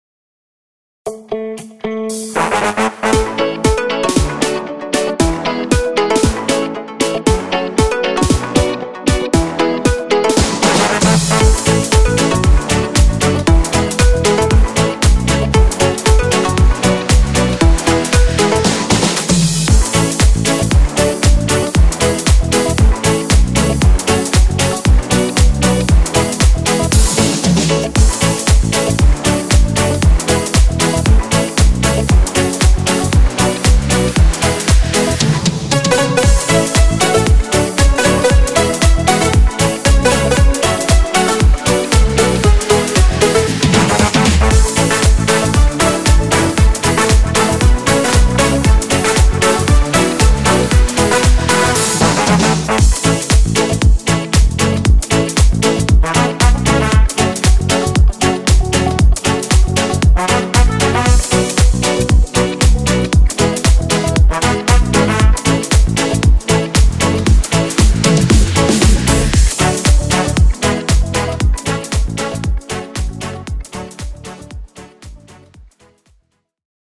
w swingującym klimacie
podkład muzyczny
Disco Polo